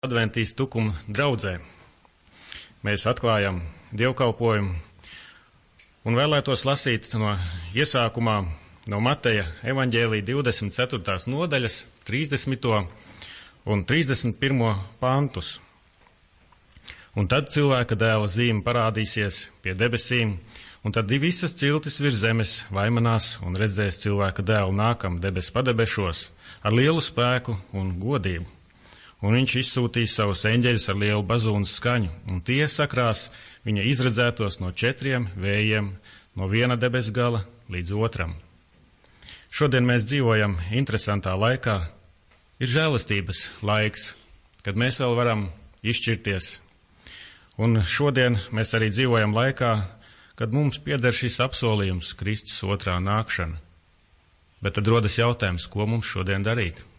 6. Atspēkošanai noder korekti piemeklēts arguments, ko man deva Dievs*, proti, fragments no Septītās dienas adventistu Tukuma baznīcas Dievkalpojuma.